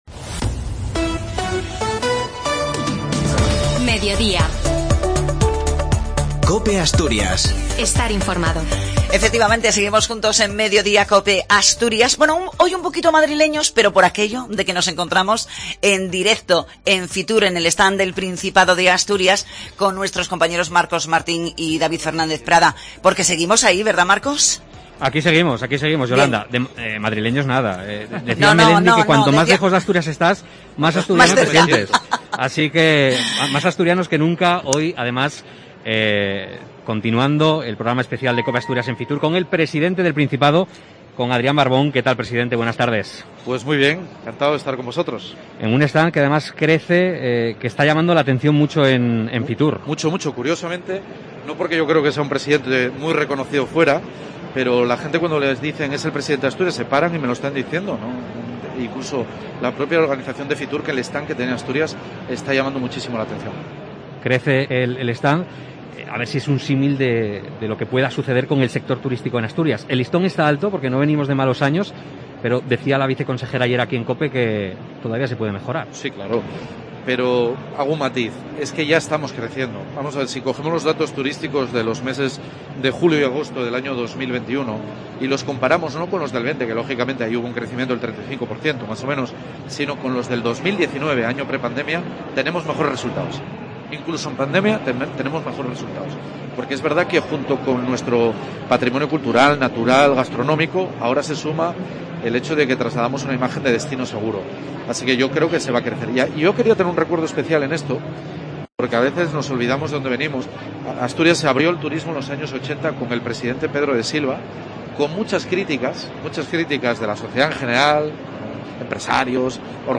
Fitur 2022: Entrevista a Adrián Barbón, presidente del Principado